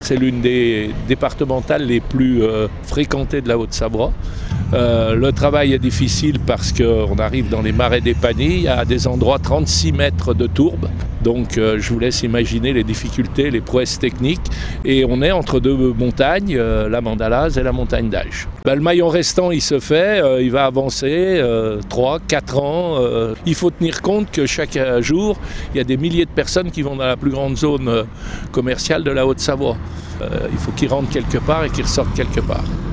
François Daviet, Conseiller départemental de La Balme et Président de la commission des finances, souligne l’importance de ce chantier pour améliorer la circulation et répondre aux besoins des habitants et des usagers.